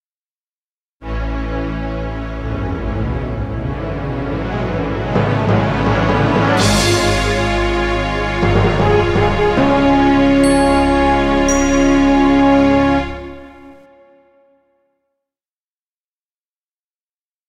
recorded from a Roland Sound Canvas SC-55mkII